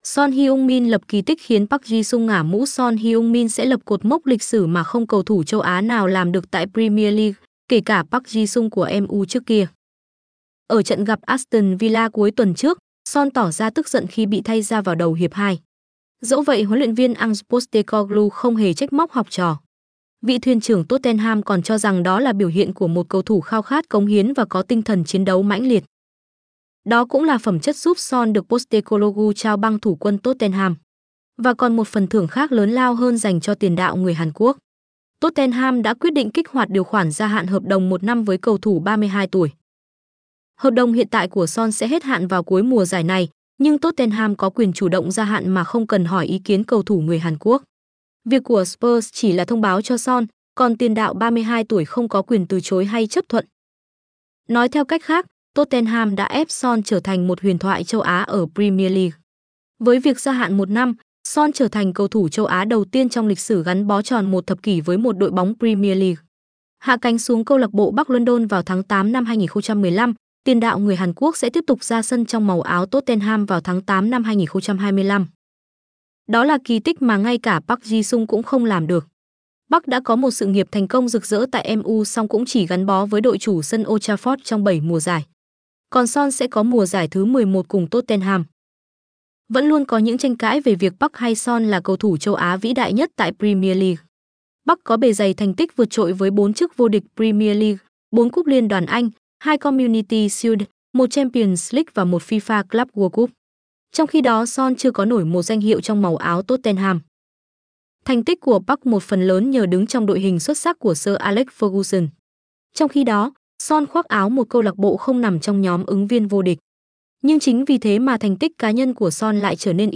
Giọng nữ